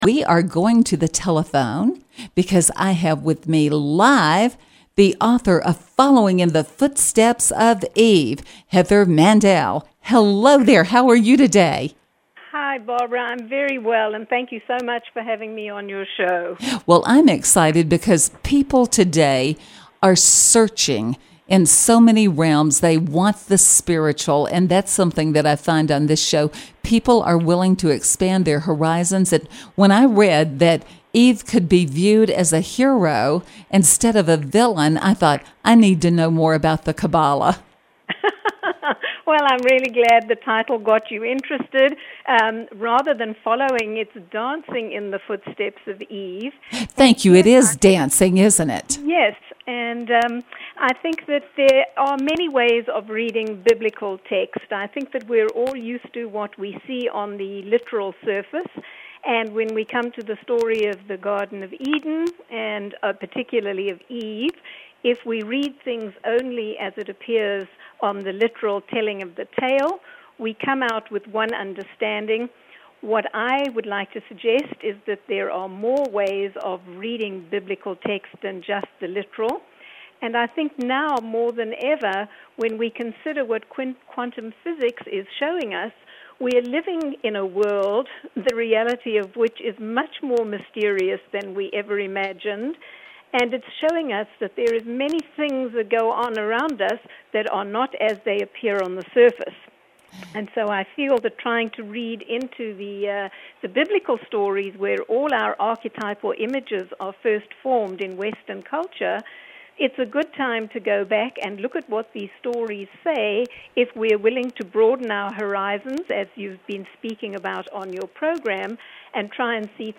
Believe_interview.mp3